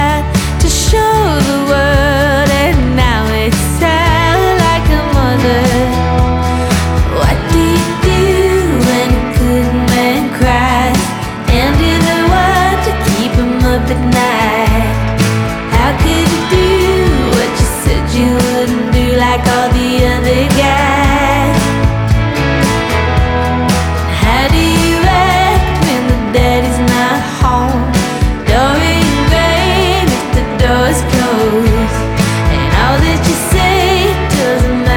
Жанр: Поп музыка / Альтернатива
Indie Pop, Alternative